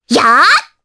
Requina-Vox_Attack4_jp.wav